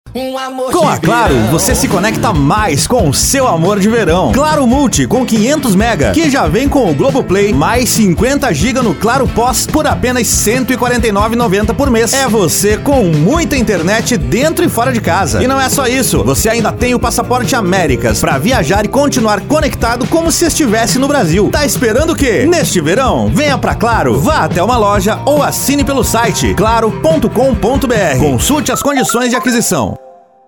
Spot Comercial
Impacto
Animada